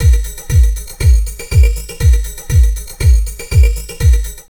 Index of /90_sSampleCDs/USB Soundscan vol.07 - Drum Loops Crazy Processed [AKAI] 1CD/Partition B/07-120FLUID